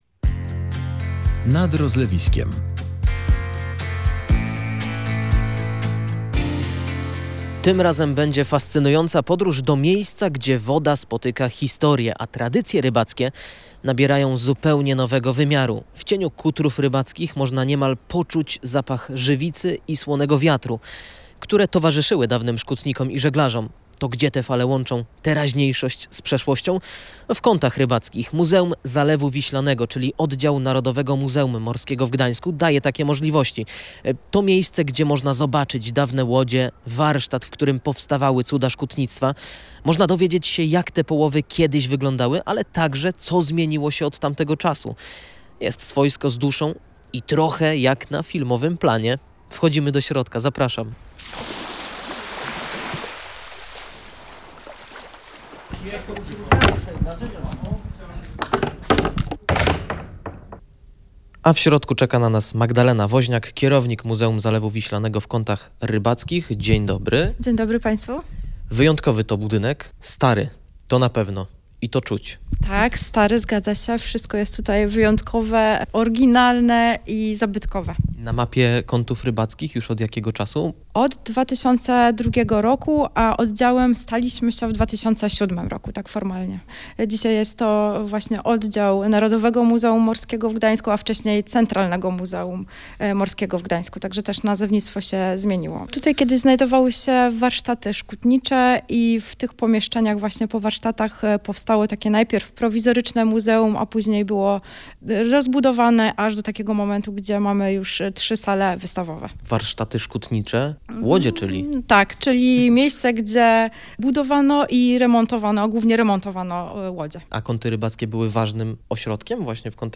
Odwiedziliśmy Muzeum Zalewu Wiślanego